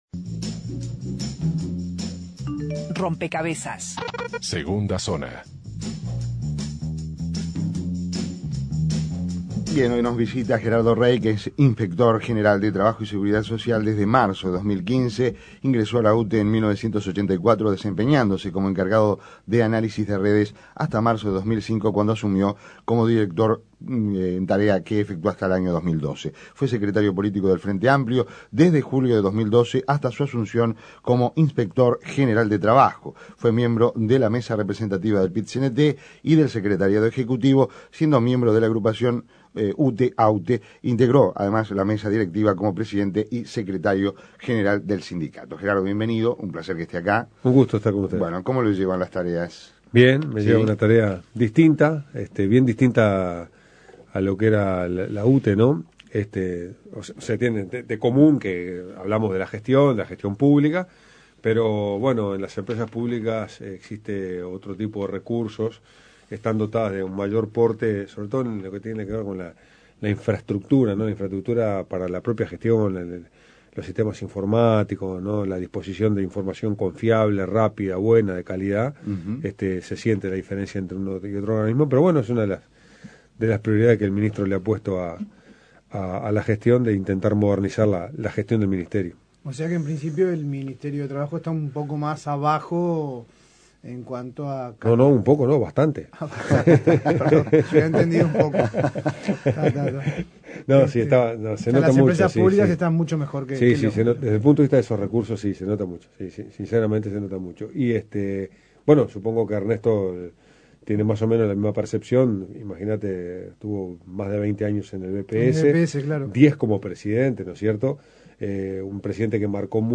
Descargar Audio no soportado Entrevista a Gerardo Rey